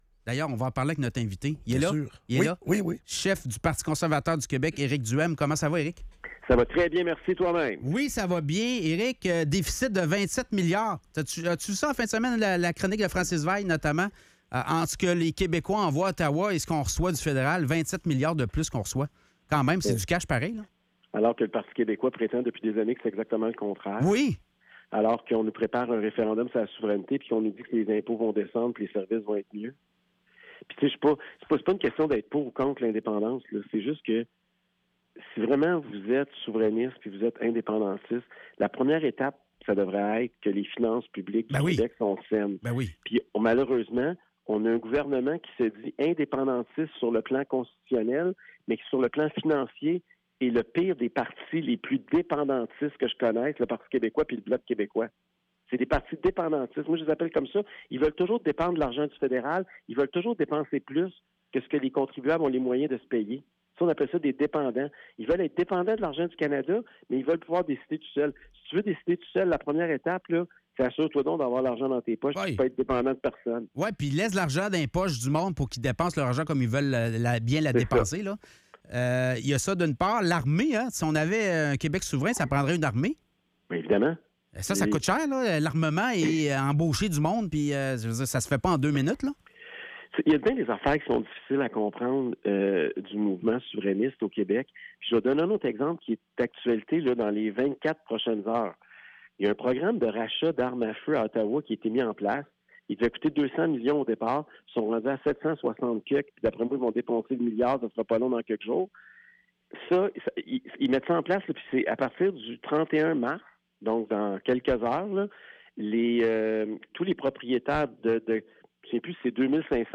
Entrevue avec Eric Duhaime, chef du PCQ